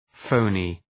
Προφορά
{‘fəʋnı}
phony.mp3